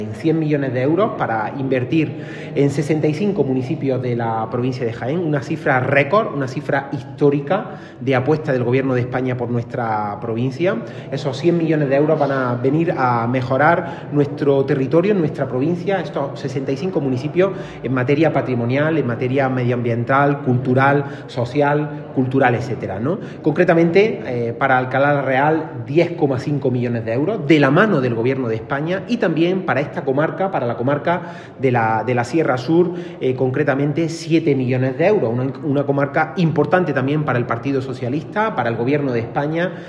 Reunión del Grupo Socialista de la Diputación en Alcalá la Real
Cortes de sonido